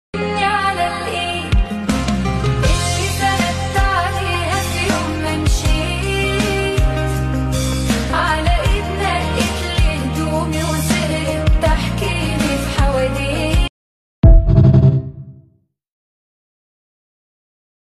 🎵 نام آهنگ : پیانو